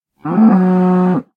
cow_say2.ogg